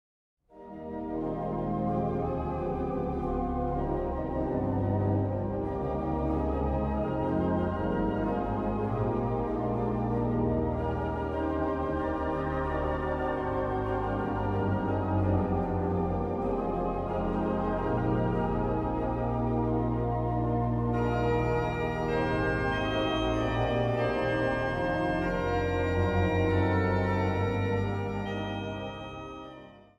Instrumentaal | Panfluit